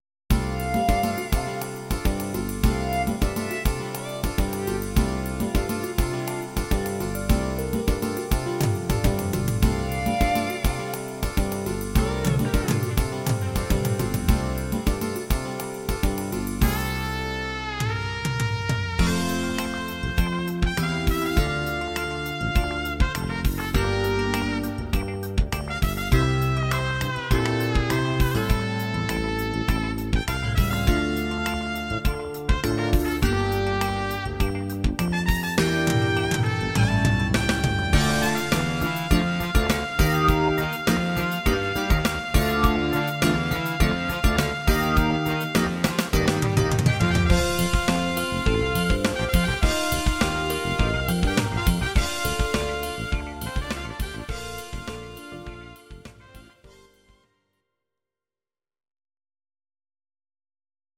Audio Recordings based on Midi-files
Pop, Jazz/Big Band, Instrumental, 1970s